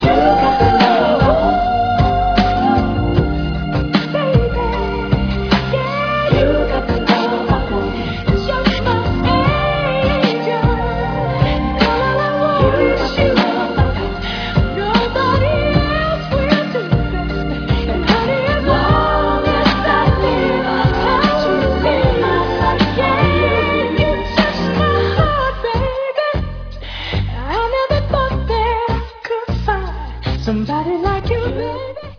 background vocals, synthesizers and drum programming